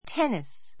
ténis